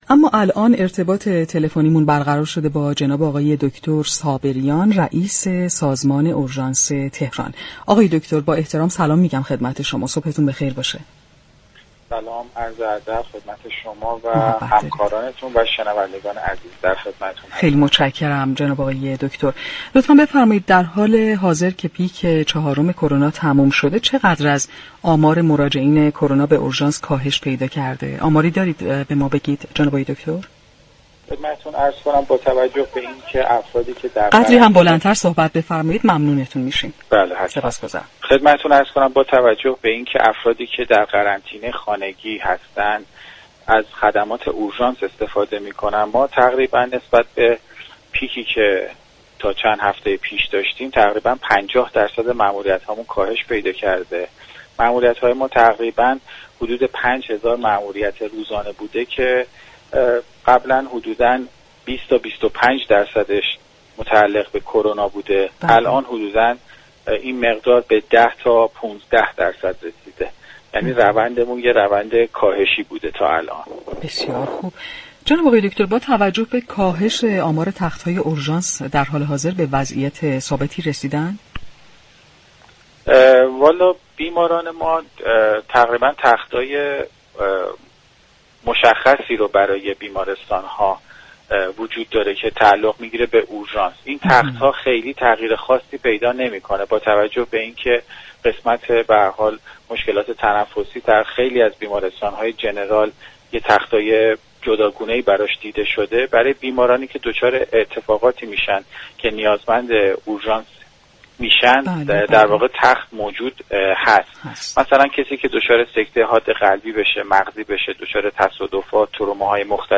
به گزارش پایگاه اطلاع رسانی رادیو تهران، پیمان صابریان رئیس اورژانس تهران در گفتگو با برنامه تهران ما سلامت رادیو تهران درباره آمار مراجعه كنندگان كرونایی به اورژانس با اتمام پیك چهارم گفت: با توجه به اینكه افرادی كه در قرنطینه خانگی هستند از خدمات اورژانس استفاده می كنند؛ نسبت به پیك چهارم 50 درصد ماموریت ها كاهش پیدا كرده است.